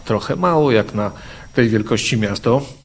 Twierdzi zastępca prezydenta Ełku Mirosław Hołubowicz i dodaje, że warto dołączyć do grona odbiorców miejskich wiadomości.